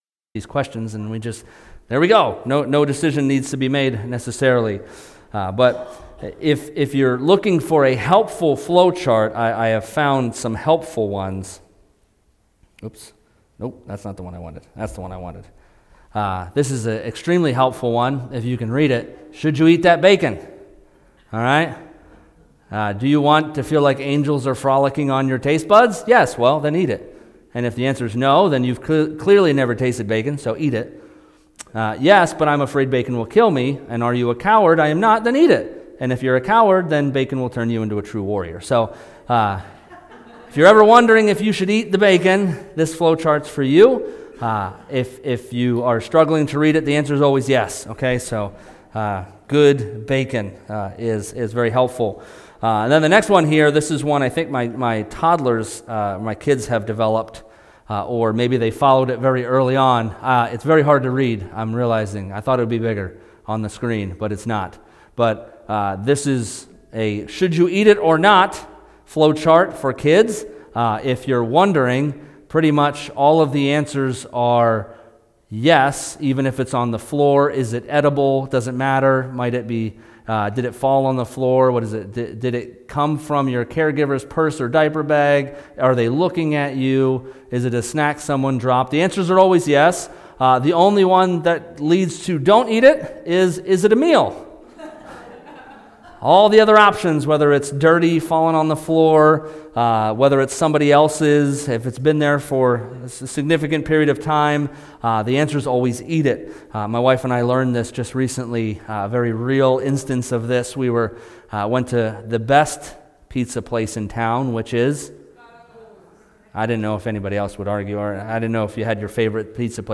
Sermons | DuBois Alliance